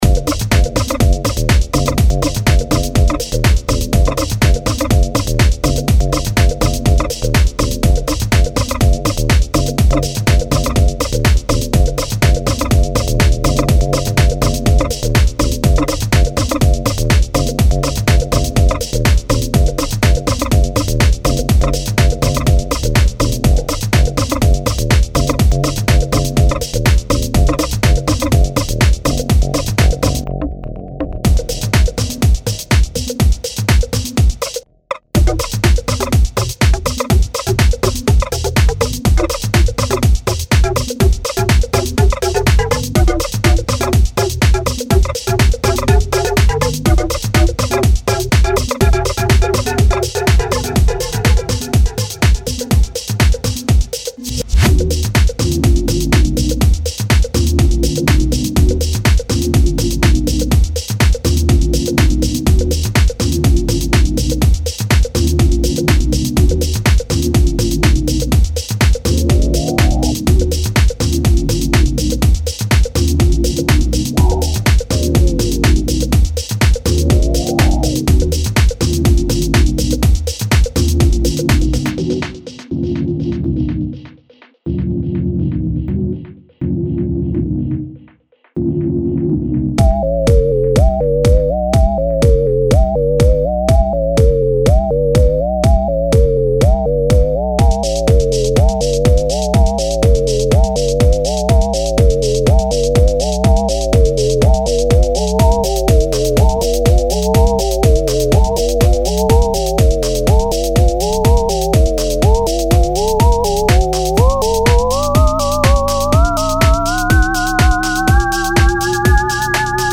Preset Sounds,native instruments,masive,bass,atmo,chord,sequence,fx,drums